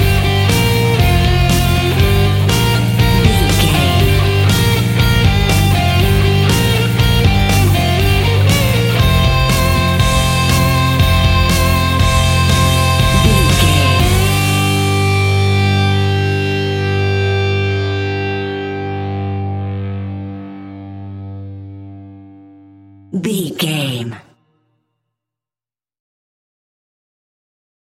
Epic / Action
Fast paced
Aeolian/Minor
hard rock
heavy metal
blues rock
distortion
rock instrumentals
Rock Bass
heavy drums
distorted guitars
hammond organ